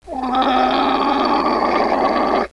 Звук крика Аааам! ламы